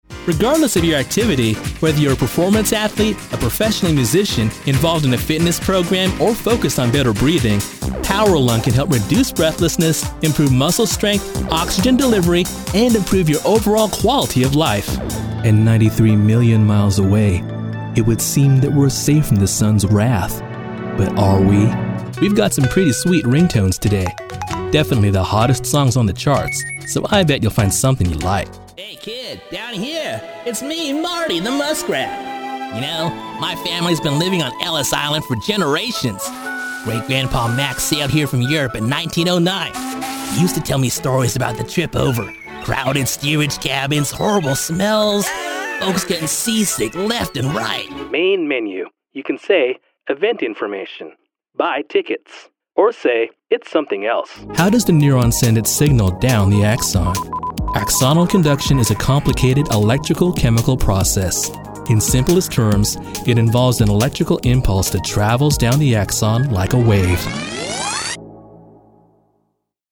Young Male Talent: I do commercials and narration.
Sprechprobe: Industrie (Muttersprache):